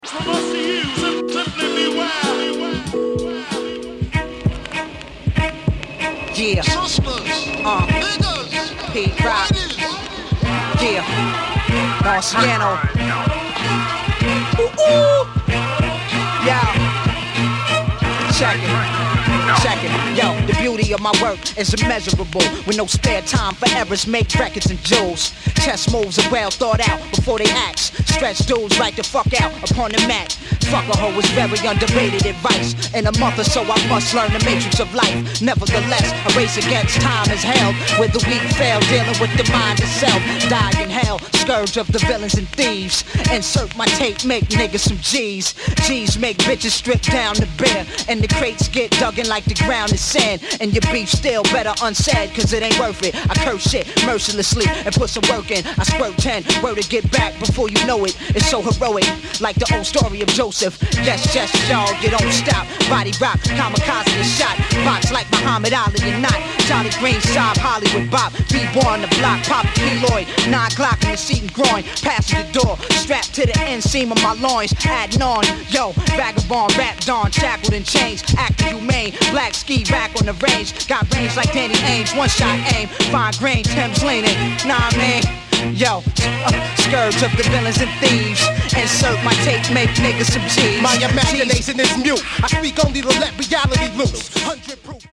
Head Nodding – cold crash your whip music